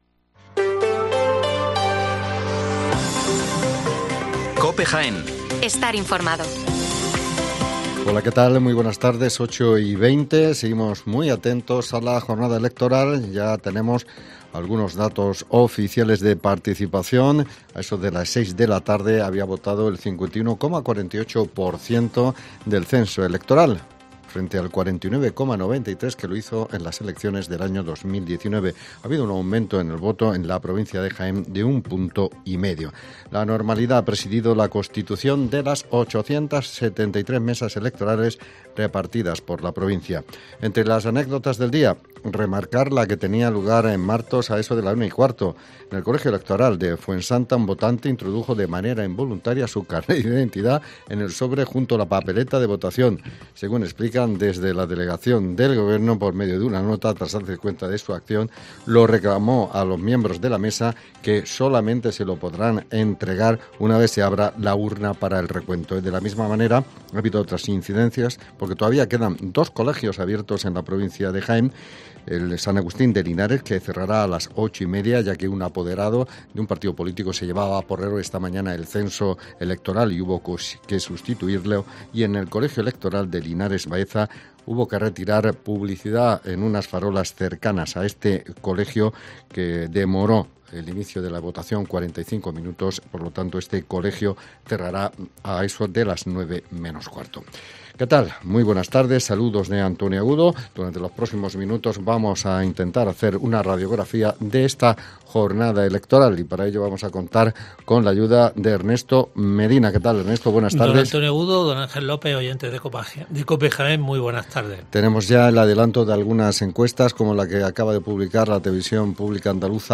Especial Elecciones Municipales en Jaén. El informativo de las 20:20 horas